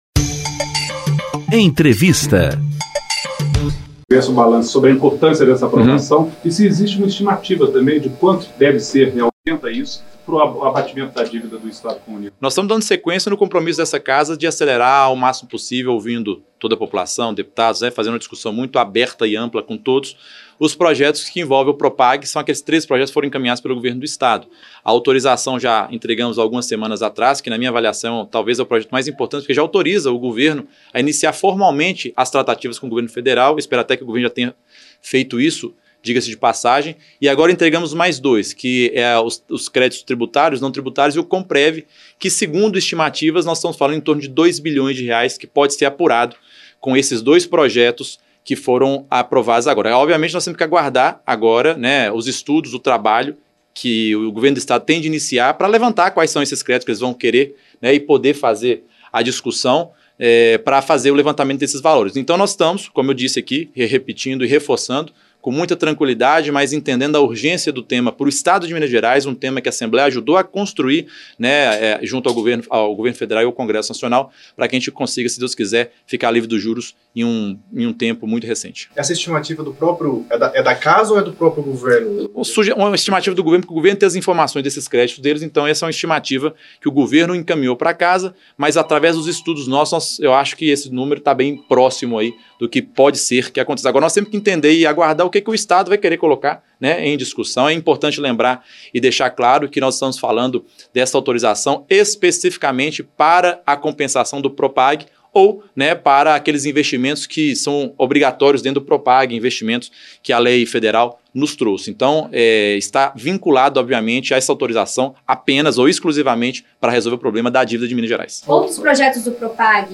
Entrevistas
Na íntegra da entrevista coletiva, o Presidente da Assembleia Legislativa explica aos jornalistas que compromisso dos deputadas e deputados é ajudar Minas a ficar livre dos juros da dívida pública com a adesão ao Programa de Pleno Pagamento de Dívidas dos Estados e aponta novos caminhos para que essa adesão se efetive.